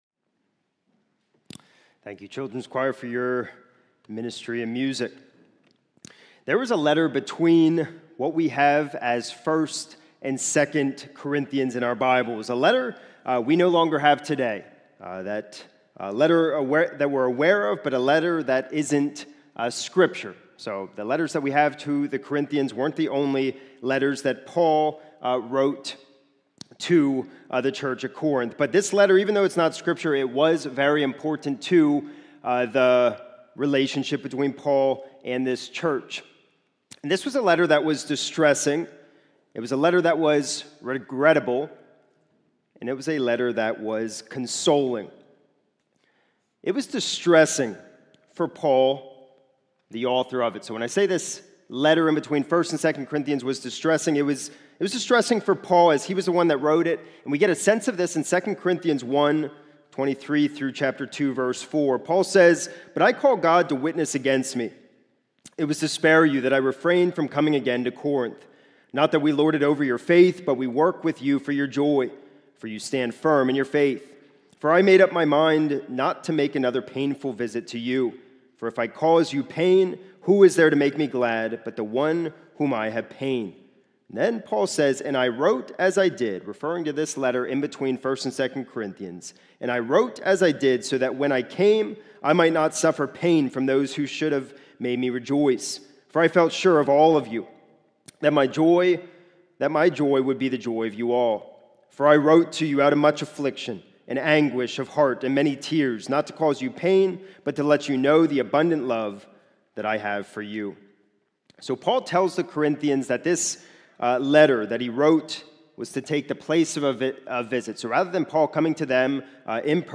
This is a sermon recorded at the Lebanon Bible Fellowship Church in Lebanon